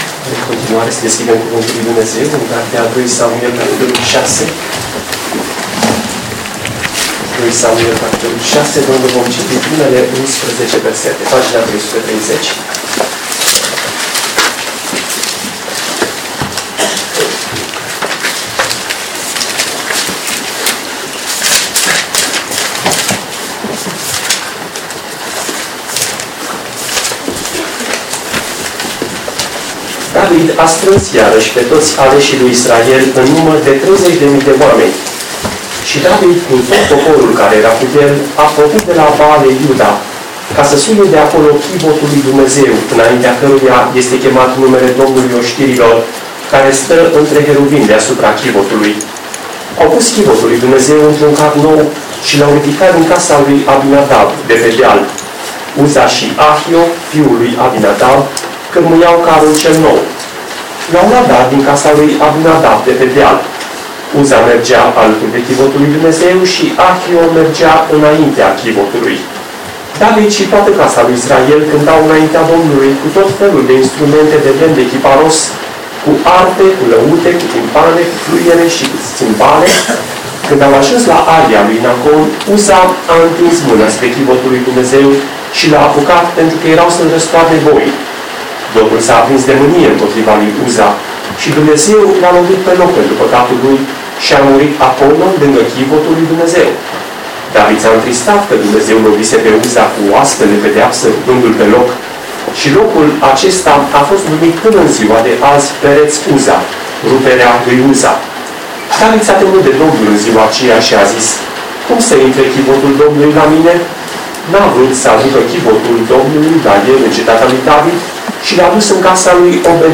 Evanghelizare, dimineața Predică, Ioan 3:1-21